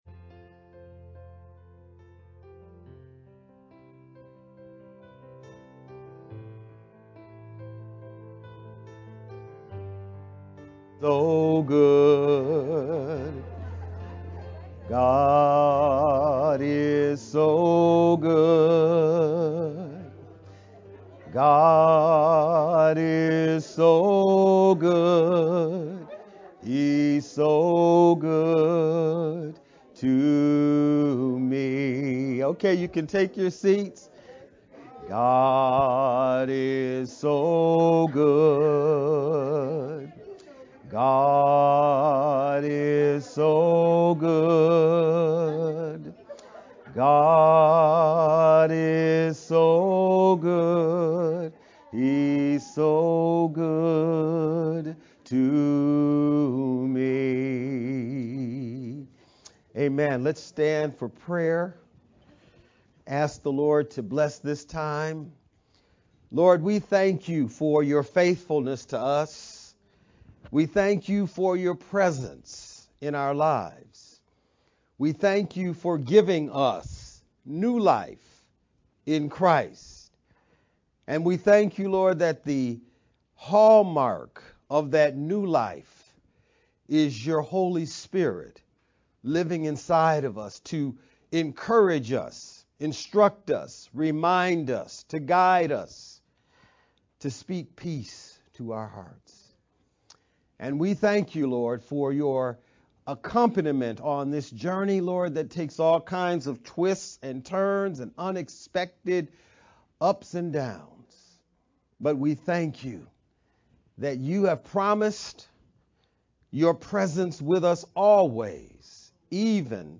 VBCC-sermon-edited-10-16-sermon-only-CD.mp3